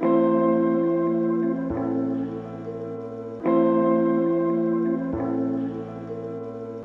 Sampled_No_Heart Strings.wav